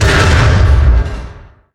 barrel explode02.wav